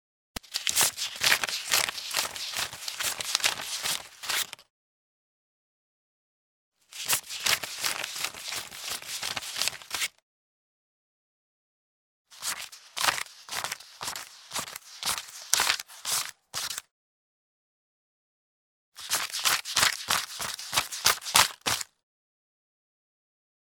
Звук пересчета купюр